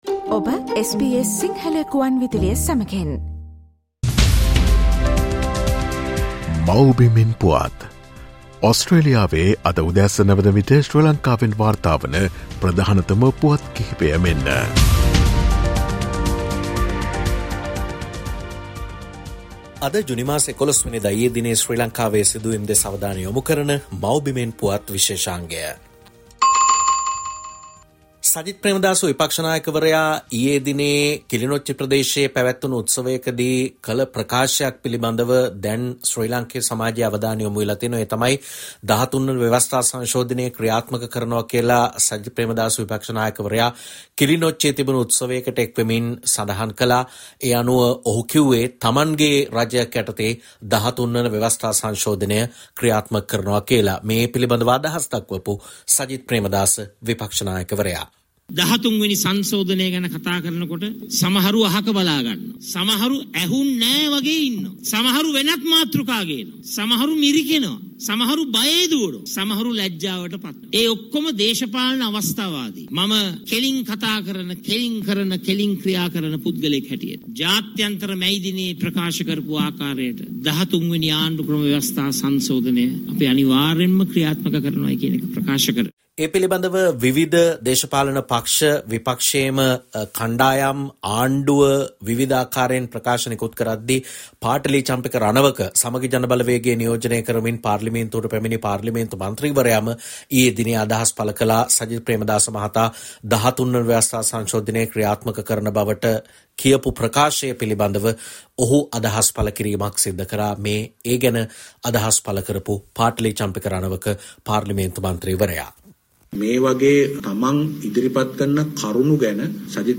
The latest news reported from Sri Lanka as of this morning in Australia time from the “Homeland News” feature